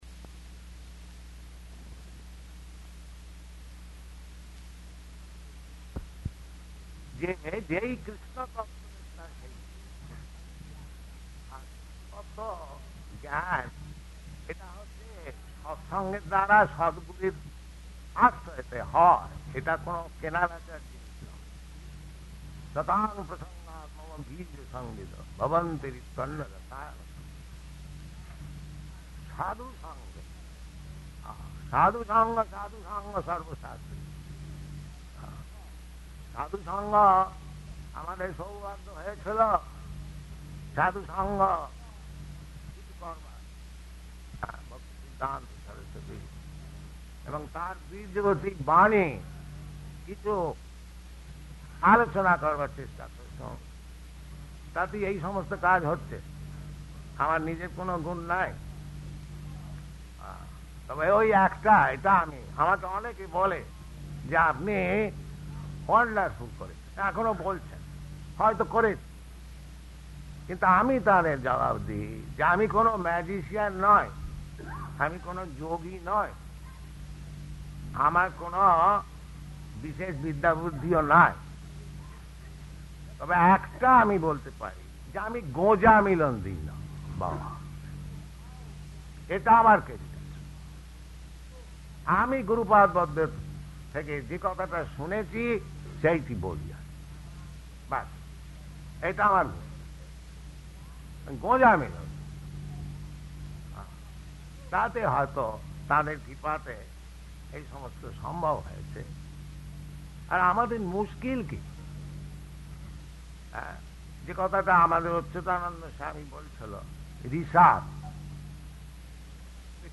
Lecture--Bengali
-- Type: Lectures and Addresses Dated
Location: Māyāpur